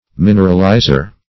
Search Result for " mineralizer" : The Collaborative International Dictionary of English v.0.48: Mineralizer \Min"er*al*i`zer\, n. An element which is combined with a metal, thus forming an ore.